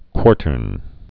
(kwôrtərn)